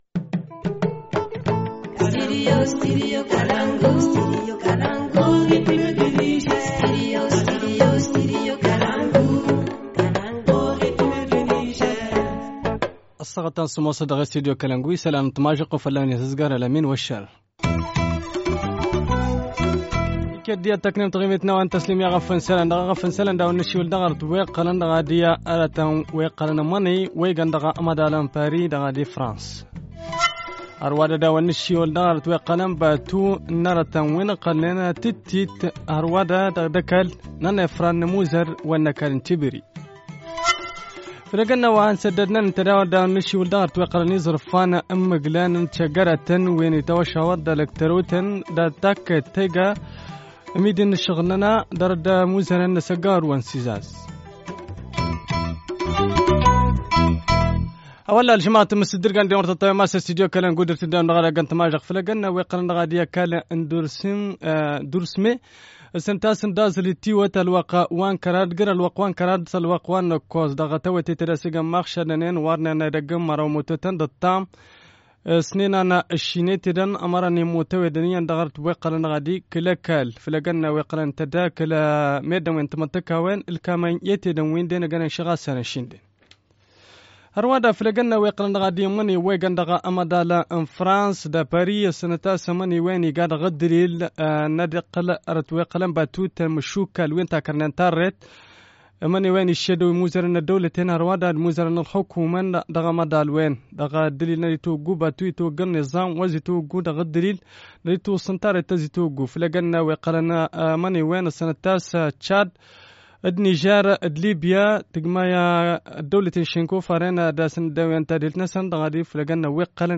Journal du 28 août 2017 - Studio Kalangou - Au rythme du Niger